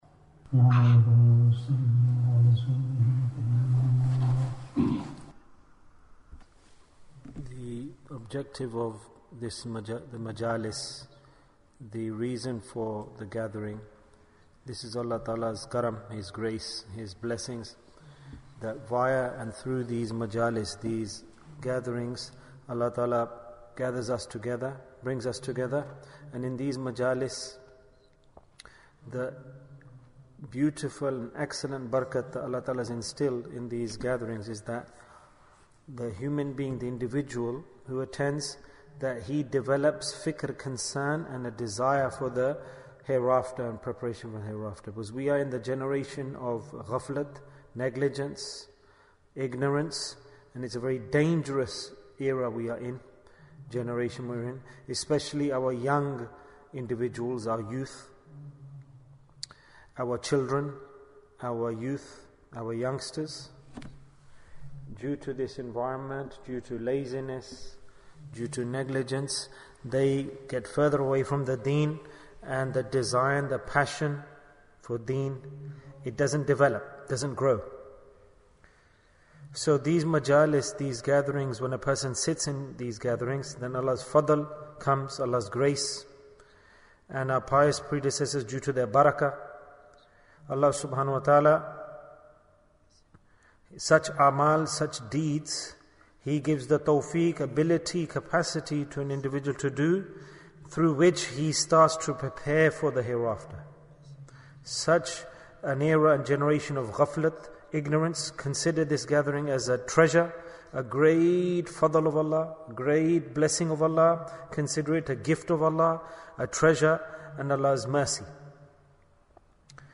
A Deed for Success Bayan, 38 minutes28th June, 2022